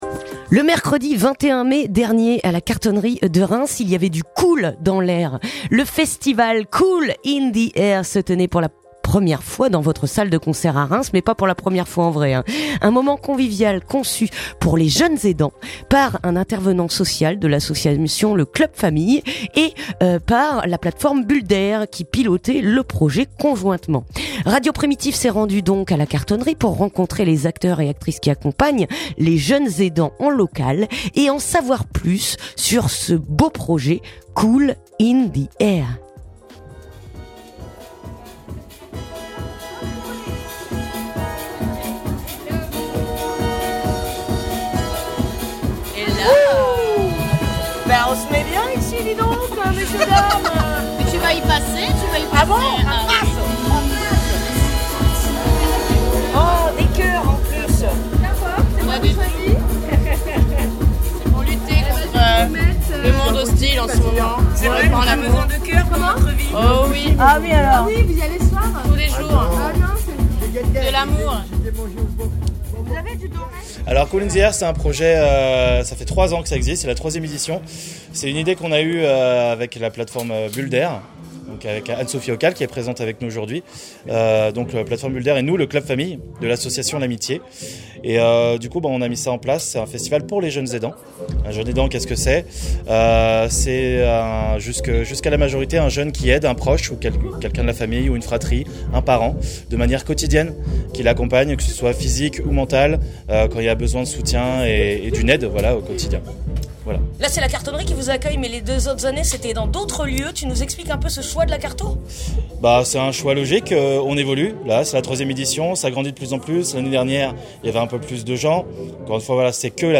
Reportage à la Cartonnerie de Reims.